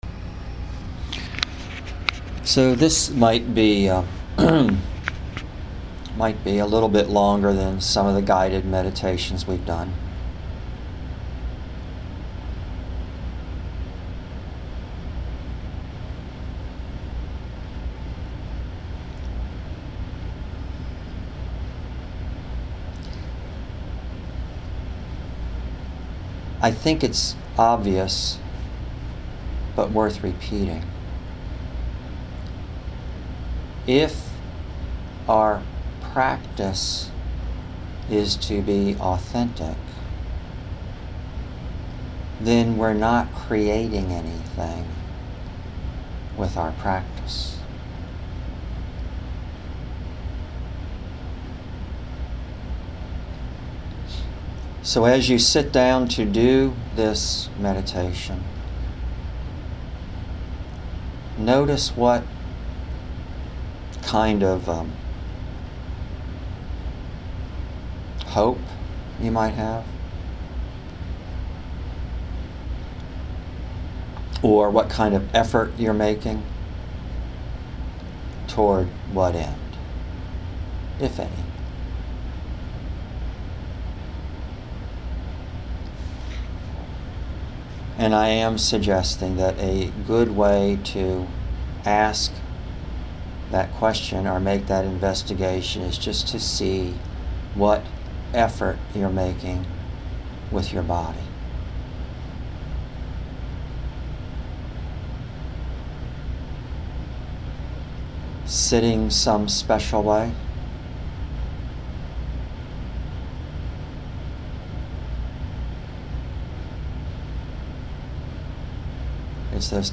GuidedMed_BecomingAuthentic.mp3